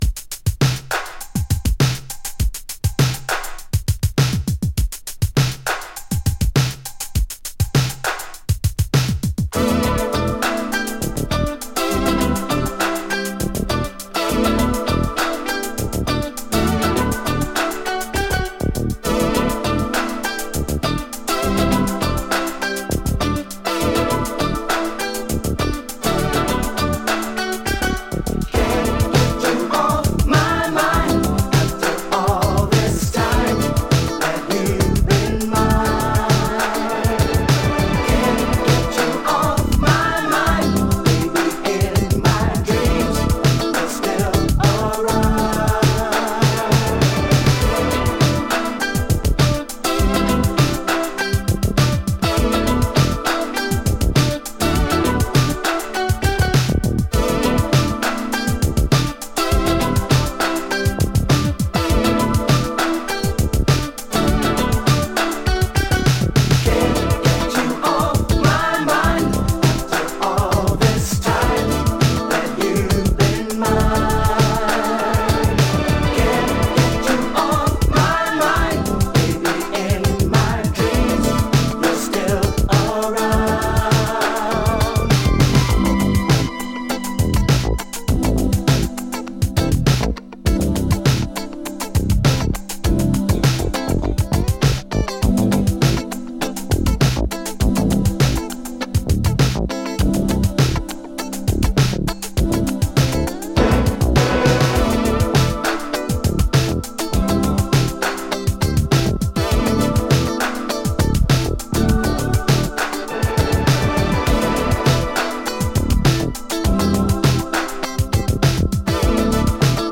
Dutch Urban Boogie!
【NETHERLANDS】【BOOGIE】